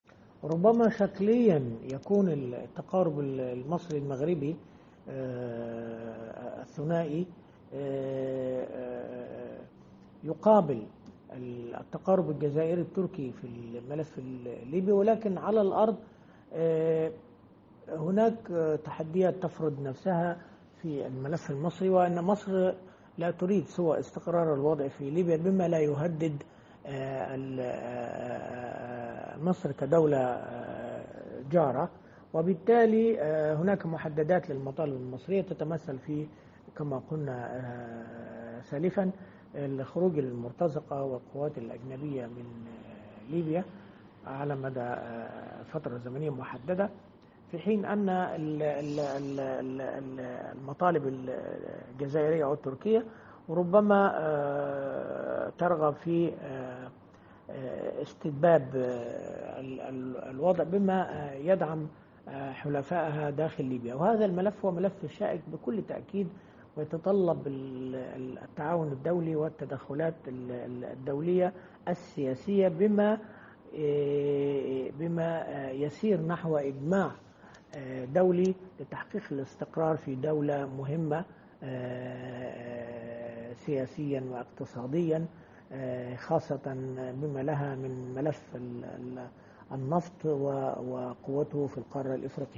الكاتب الصحفي والمحلل السياسي